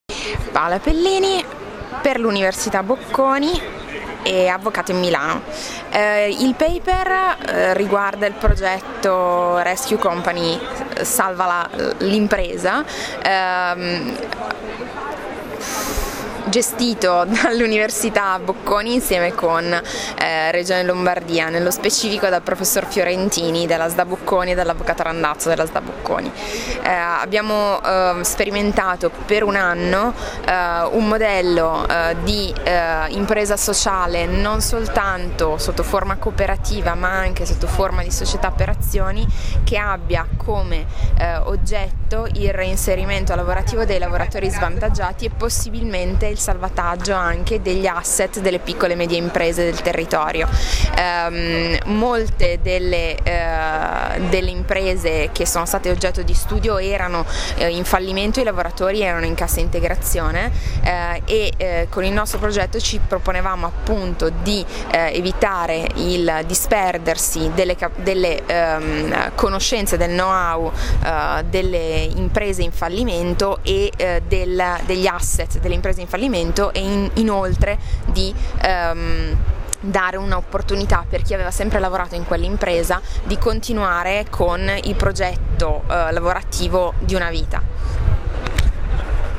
Il Colloquio Scientifico sull’impresa sociale, edizione VII, si è chiuso la scorsa settimana a Torino.
A ricordo di alcuni dei loro interventi, delle brevi audio interviste mordi e fuggi sui loro temi di ricerca.